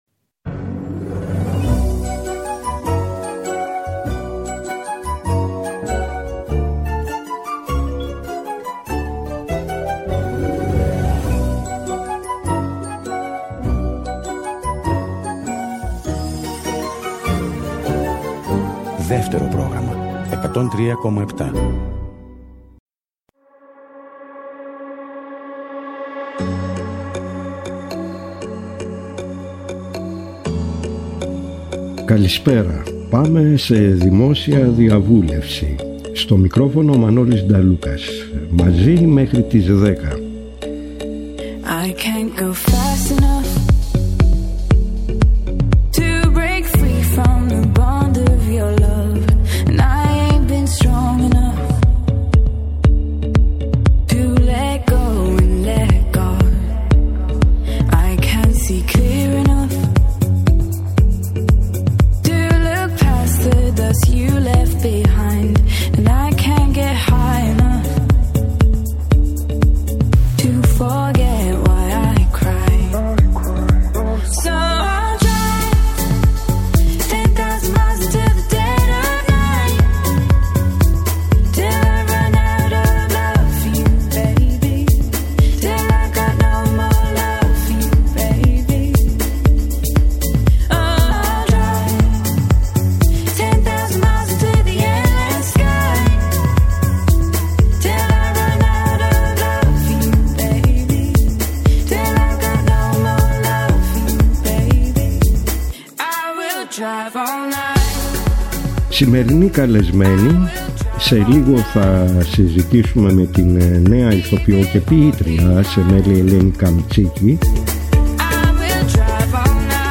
δίνει συνέντευξη